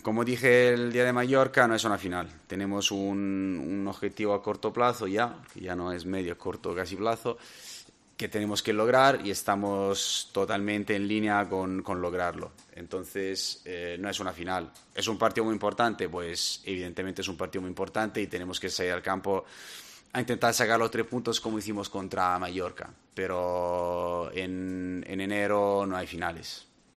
“Tenemos un objetivo a corto plazo que tenemos que lograr y estamos en el camino. Es un partido muy importante y tenemos que intentar sacar los tres puntos, pero en enero no hay finales”, dijo el entrenador en la rueda de prensa previa al partido.